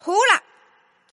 /client/common_mahjong_tianjin/mahjonghntj_ios_newplayer_test/update/1288/res/sfx/tianjin/woman/